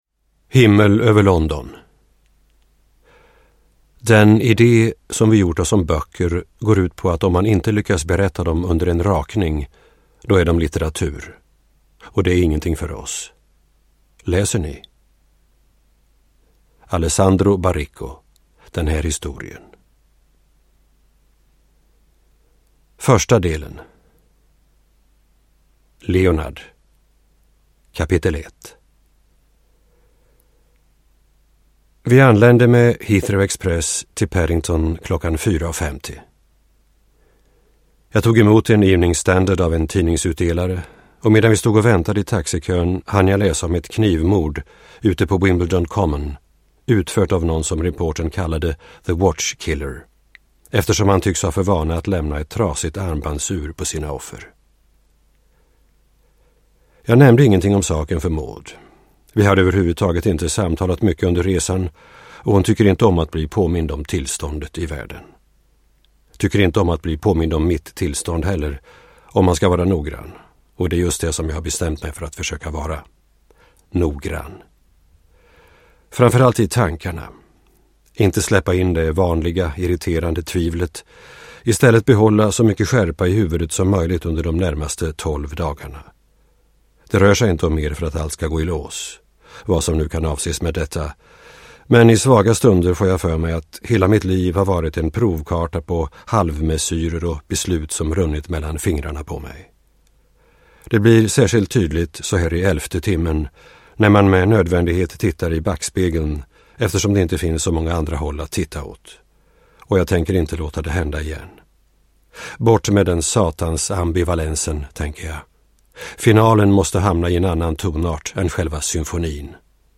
Uppläsare: Håkan Nesser